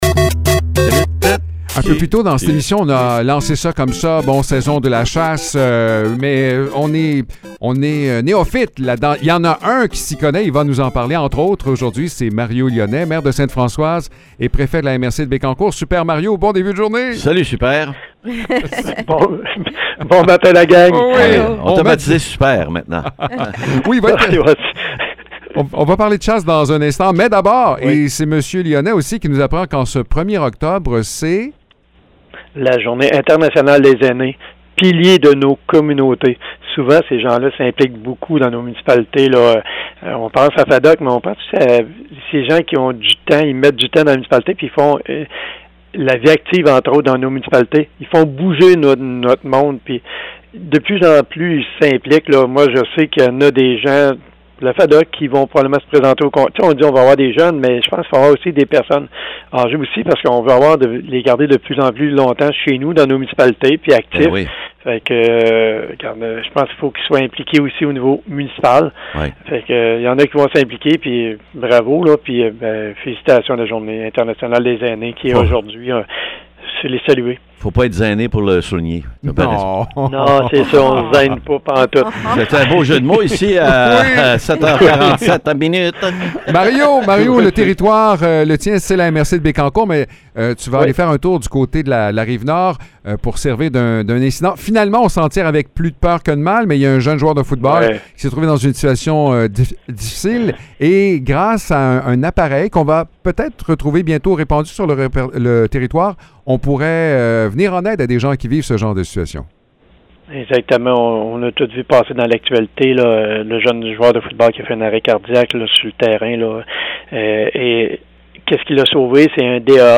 Mario Lyonnais, maire du village de Sainte-Françoise et préfet de la MRC de Bécancour, nous partage quelques conseils de chasse, notamment en lien avec les conditions météorologiques. Nous revenons également sur le cas d’un jeune joueur de football ayant subi un arrêt cardiaque, un événement qui soulève d’importantes réflexions sur l’accessibilité aux Défibrillateurs Externes Automatisés.